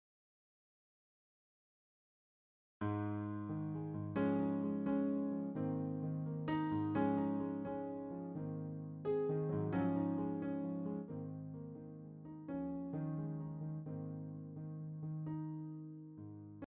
Как правильно записать нотами партию Piano?